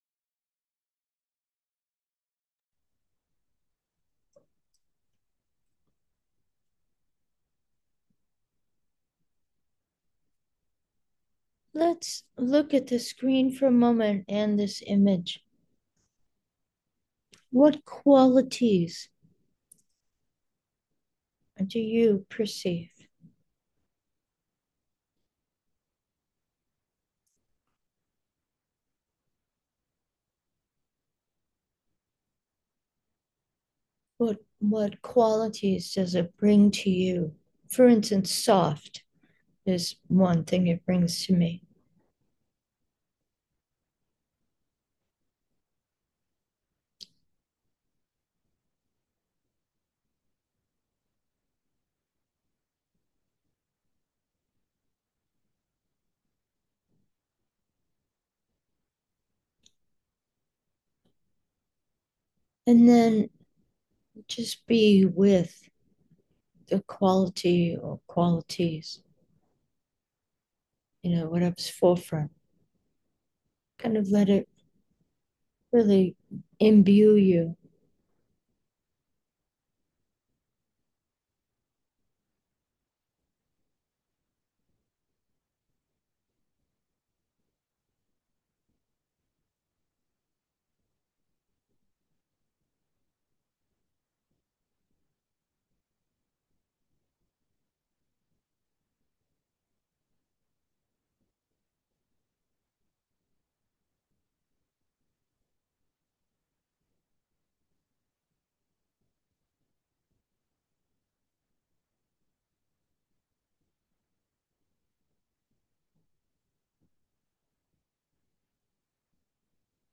Meditation: freshly 12, be the lotus